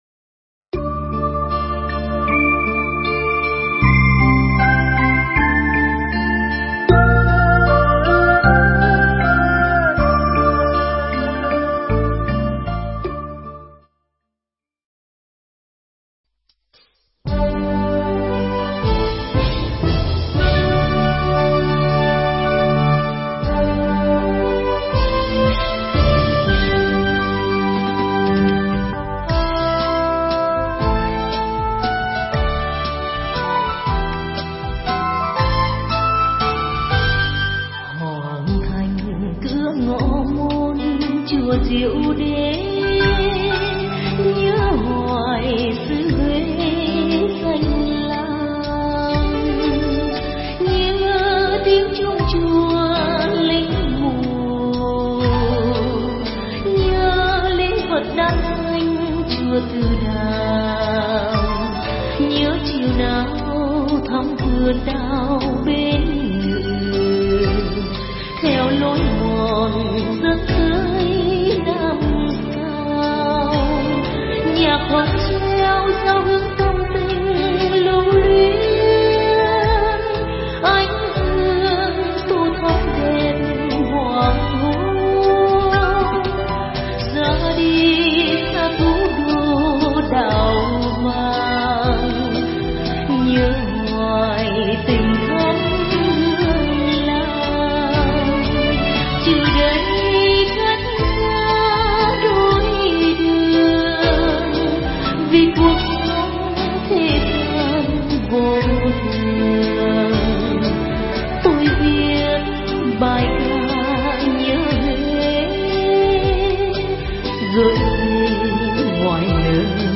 Nghe Mp3 thuyết pháp Tam Nghiệp Thân Khẩu Ý